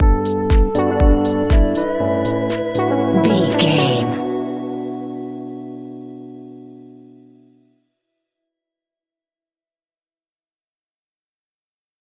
Tropical Dance Groove Stinger.
Aeolian/Minor
funky
groovy
uplifting
driving
energetic
drum machine
synthesiser
electric piano
house
electro house
instrumentals